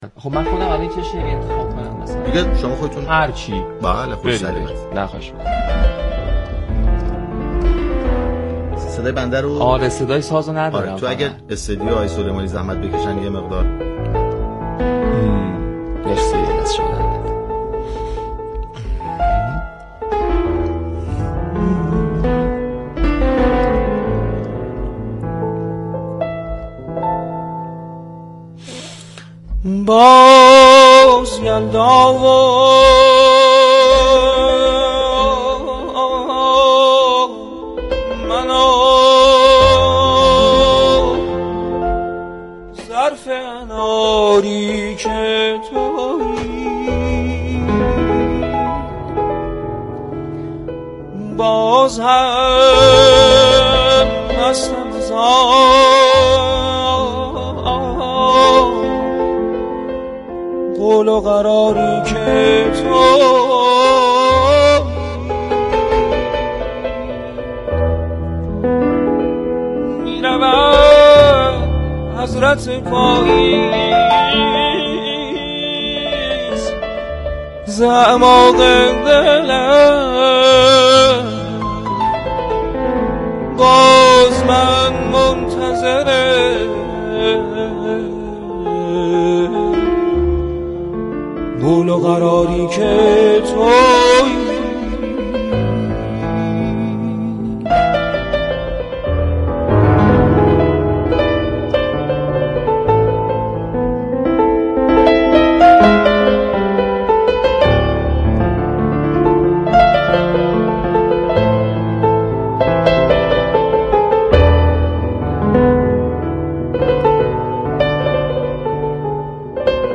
آواز خوانی زنده خواننده و نوازنده بین المللی روی آنتن صبا
این برنامه در اولین روز زمستانی از شب یلدا یاد كردند و مهمان برنامه در ابتدای گفتگو از آداب و رسوم زیبای ایرانیان و توجه به همنوع در روزهای عید و دورهمی ها گفت. مهمان برنامه برای مخاطبان اشعار حافظ را دكلمه و آواز خوانی كرد و به صورت زنده آوازهایی را تقدیم مخاطبان كرد.